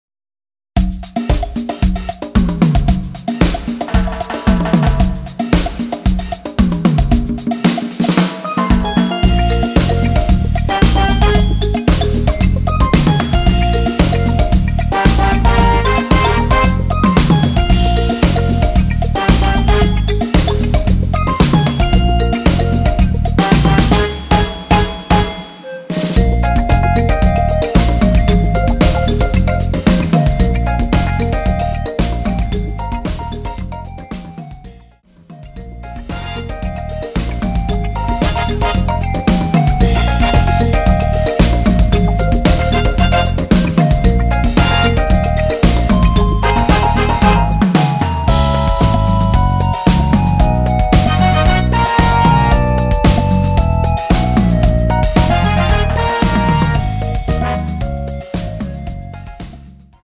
원키에서 (+4)올린 멜로디 포함된 MR 입니다.
Ab
앞부분30초, 뒷부분30초씩 편집해서 올려 드리고 있습니다.